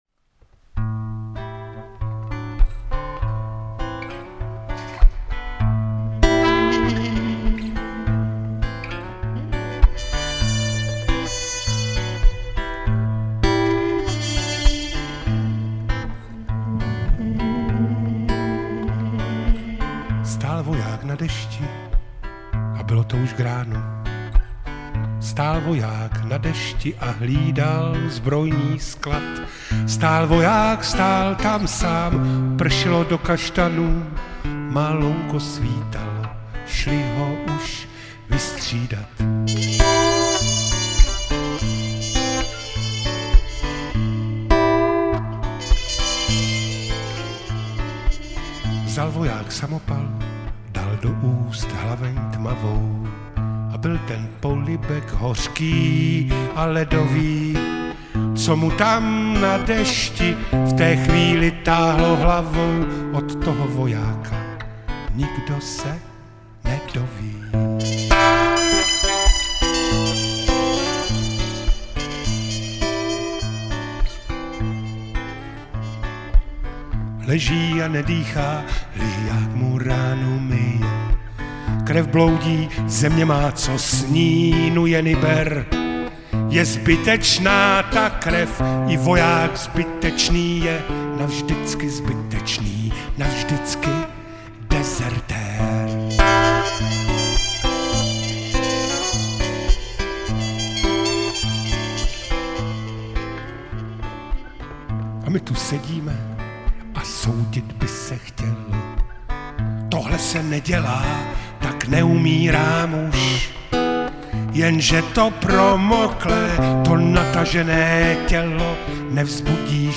společný koncert